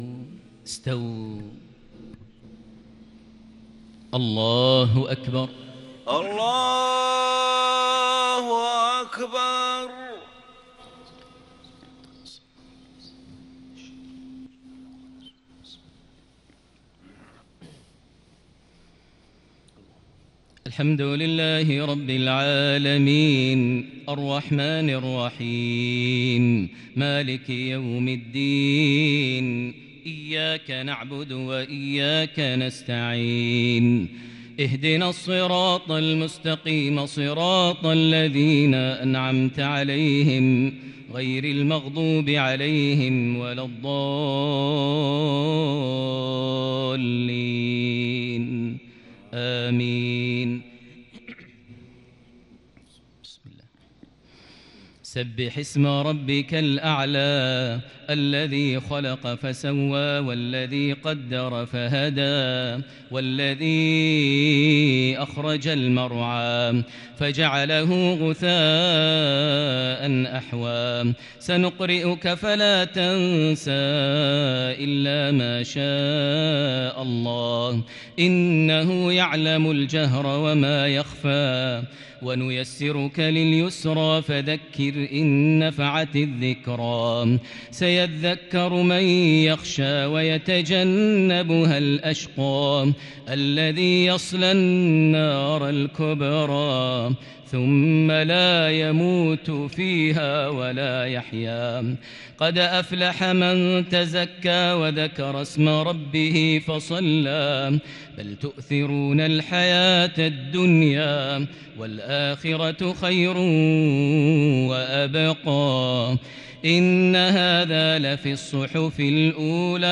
صلاة الجمعة تلاوة بديعة لسورتي الأعلى - الغاشية | 29 ذو القعدة 1442هـ > 1442 هـ > الفروض - تلاوات ماهر المعيقلي